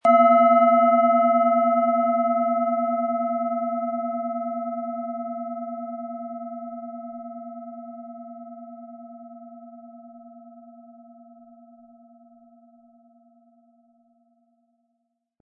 Planetenton 1
Alte Klangschalen sprechen mit ihren sanften und tragenden Schwingungen nicht nur unser Gehör, sondern auch unsere Intuition und unseren innersten Kern an.
• Klangbild: Tiefe, lang anhaltende Resonanz, klang-therapeutische Prozesse, Meditation und die Arbeit mit dem Unbewussten.
Um den Original-Klang genau dieser Schale zu hören, lassen Sie bitte den hinterlegten Sound abspielen.
Der passende Klöppel ist kostenlos dabei, der Schlegel lässt die Klangschale harmonisch und wohltuend anklingen.